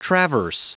Prononciation du mot traverse en anglais (fichier audio)
Prononciation du mot : traverse